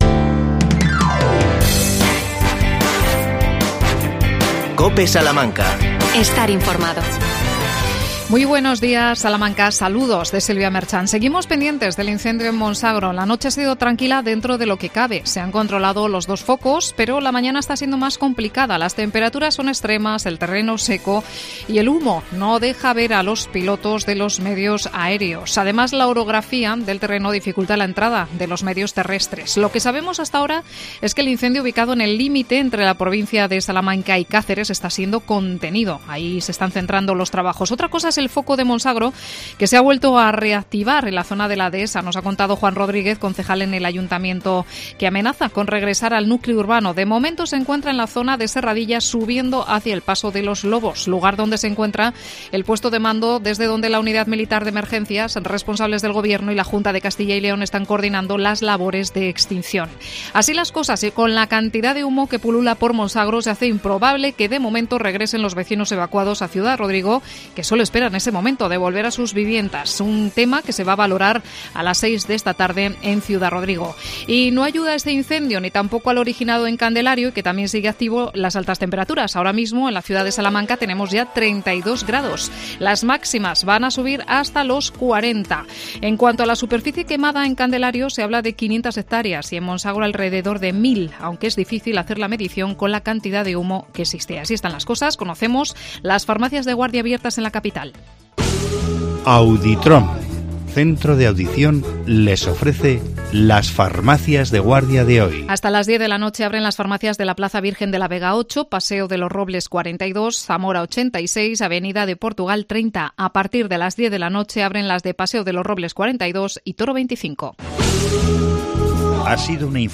AESBUS, Asociación de Empresarios de Autobus. Entrevistamos